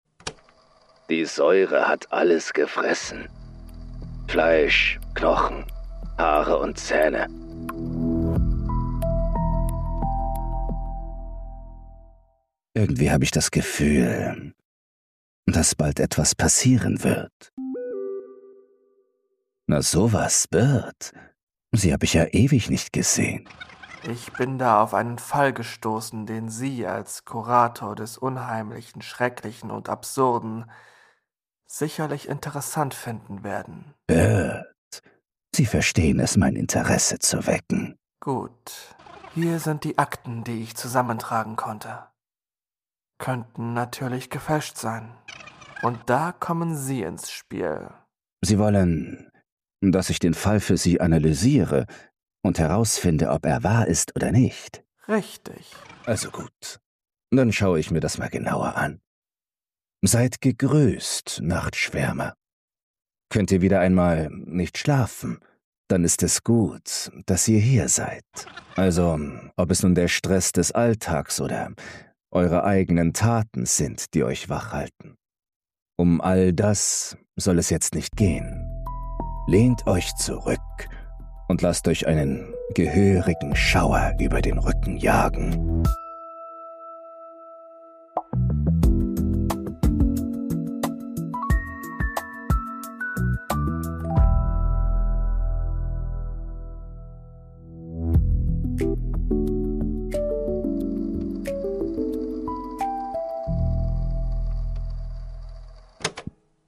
einschalten, um kein True Crime-Hörspiel zu verpassen.
Hörbuch-Podcast zwischen True Crime und Mystery – zum Miträtseln,